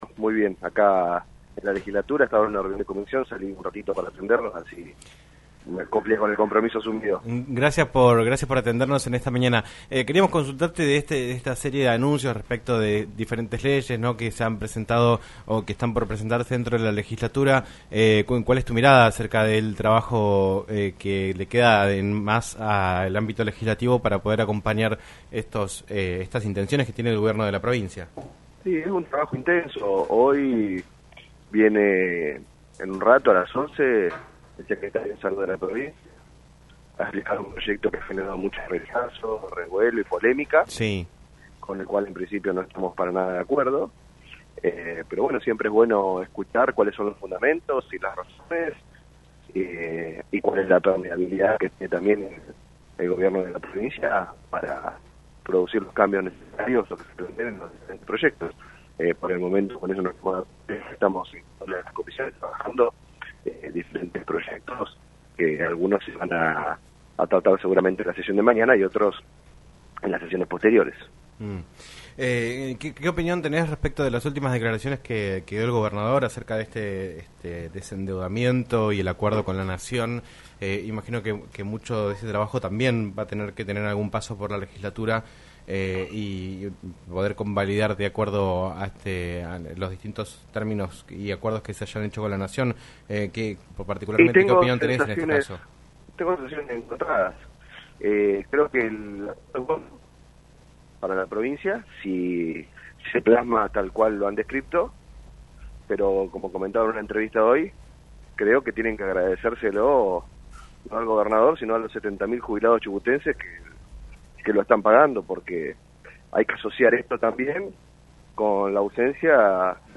Juan Pais, diputado provincial por el bloque Arriba Chubut, habló en “Un Millón de Guanacos” por LaCienPuntoUno sobre los anuncios de diferentes leyes que se están por presentar en la Legislatura para alcanzar el desendeudamiento de la provincia.